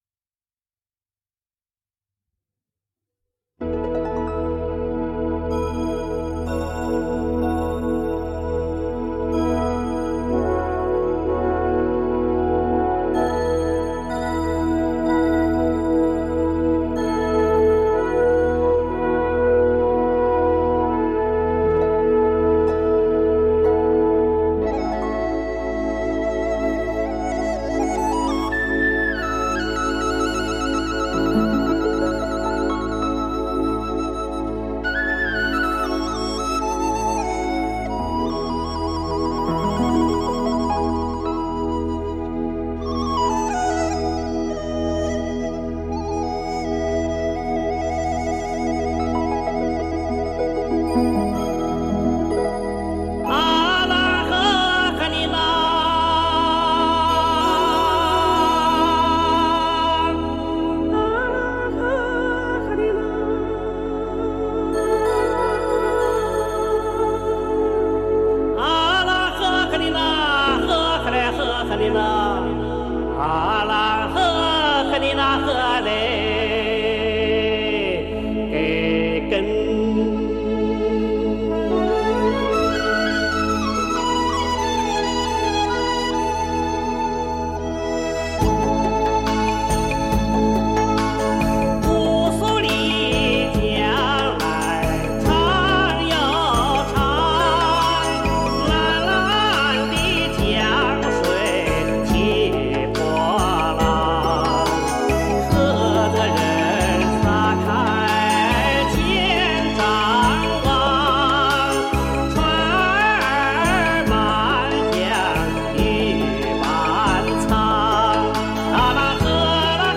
这个系列的磁带制作精良，采用了国内磁带很少使用的杜比B NR及HX PRO技术，音响效果非常不错。
磁带数字化